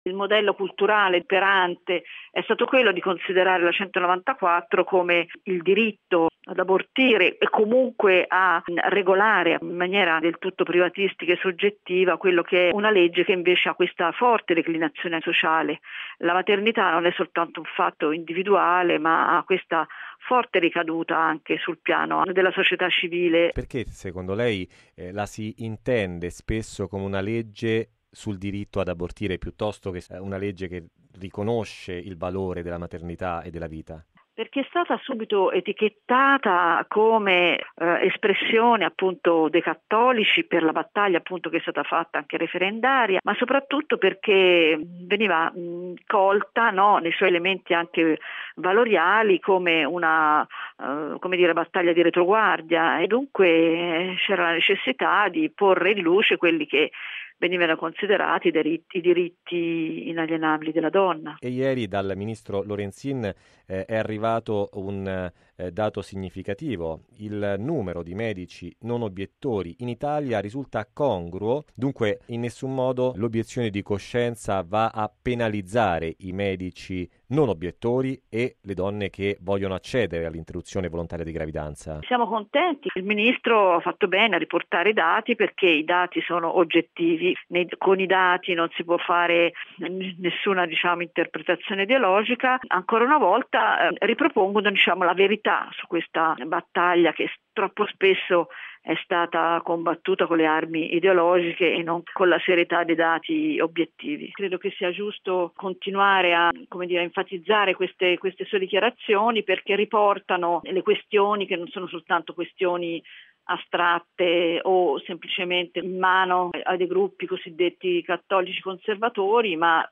l’ha intervistata: